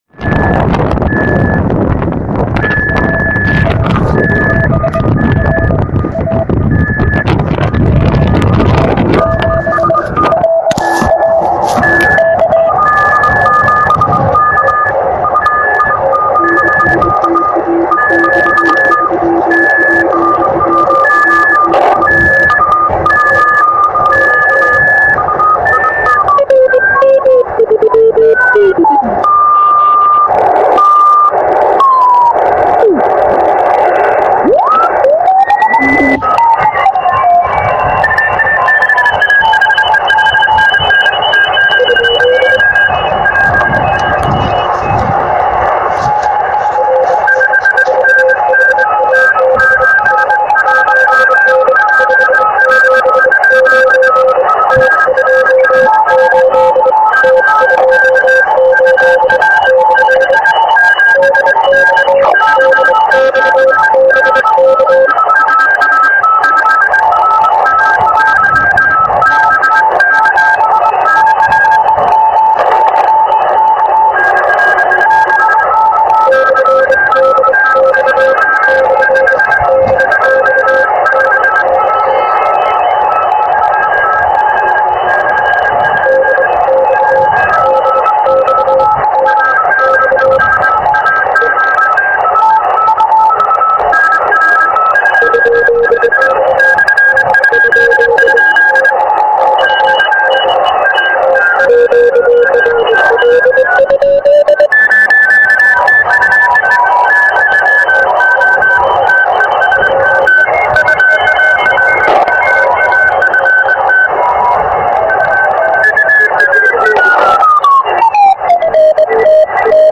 Вот короткая запись, сделанная в конце теста на 20 м. Вложенный файл
13,258 KB Это для тех, кто хотел послушать эфир в 5B-land (KM64TV). Запись велась на диктофон в присутствии сильного ветра, потому есть звуковые артефакты.